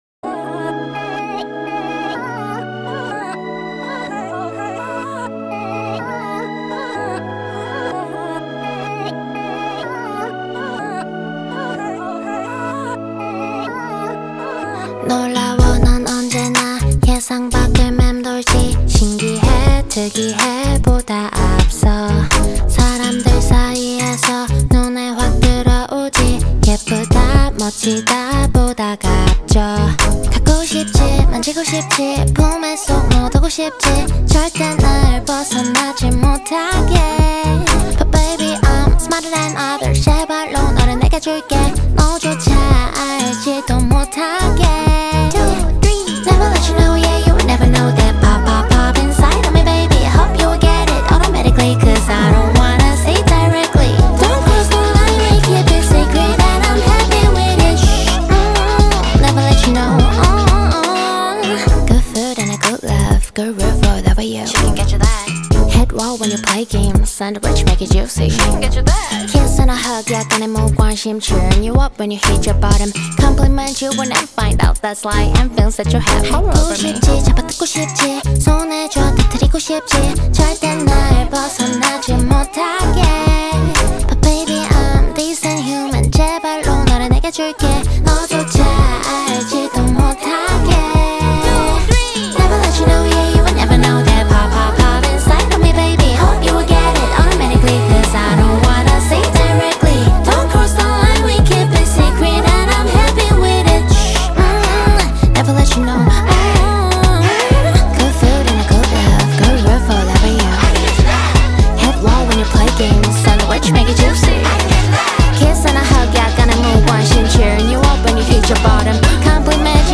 BPM125-125
Audio QualityPerfect (High Quality)
R&B song for StepMania, ITGmania, Project Outfox
Full Length Song (not arcade length cut)